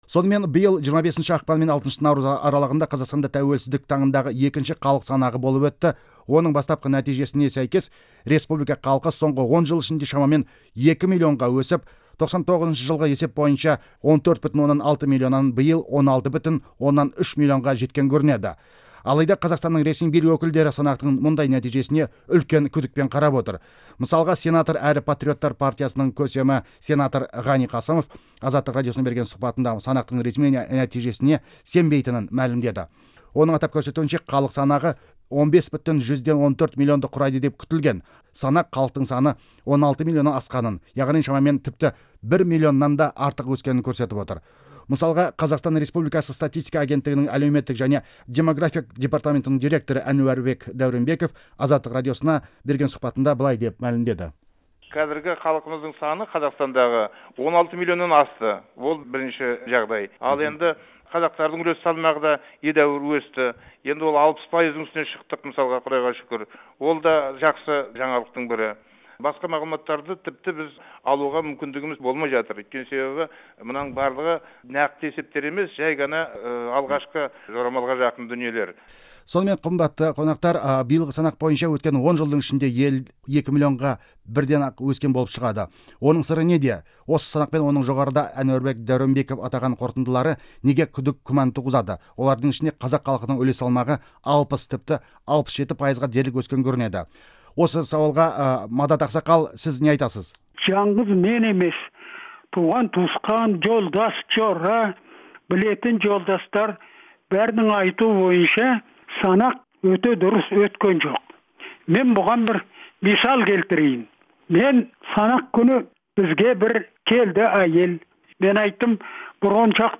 Халық санағы туралы дөңгелек үстел сұхбатын тыңдаңыз